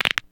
Associated sound effects
[knuckle crack]
Taunt_knuckle_crack.wav